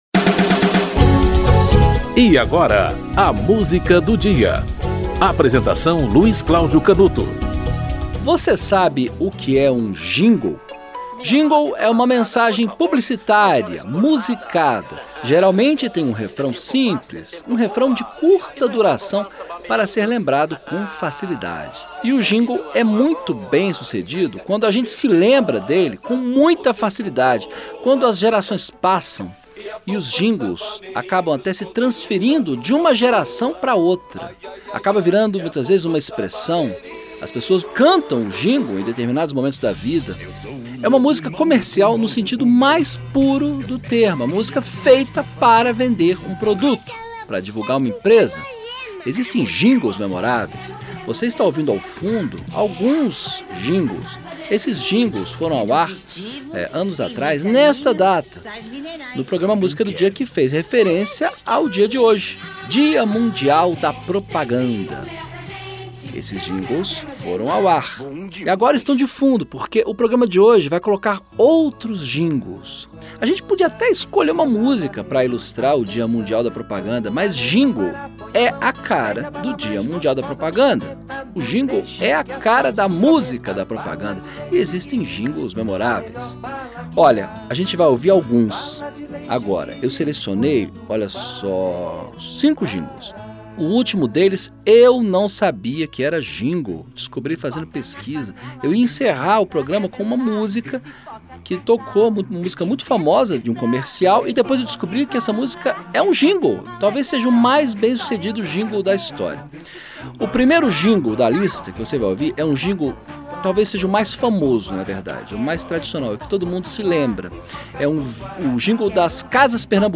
Música do dia: hoje não tem uma música, mas cinco célebres jingles